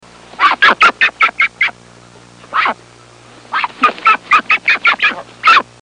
Duck sound